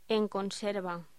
Locución: En conserva